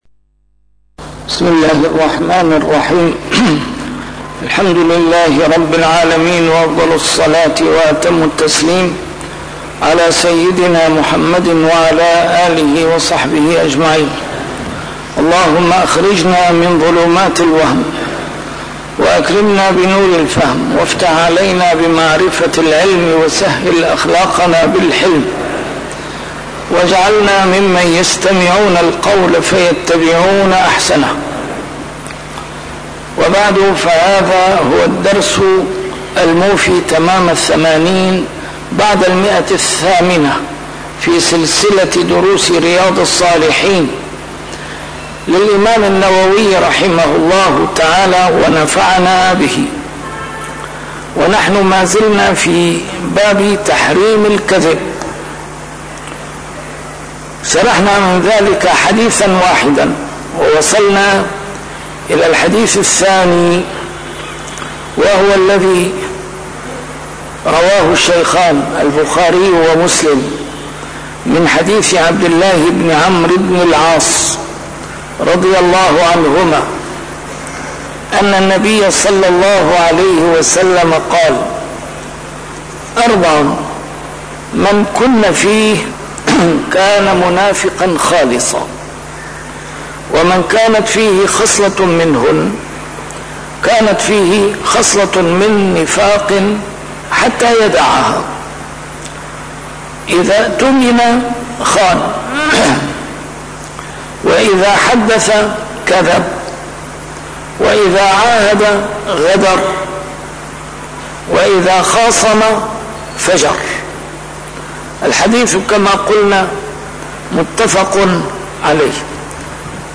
A MARTYR SCHOLAR: IMAM MUHAMMAD SAEED RAMADAN AL-BOUTI - الدروس العلمية - شرح كتاب رياض الصالحين - 880- شرح رياض الصالحين: تحريم الكذب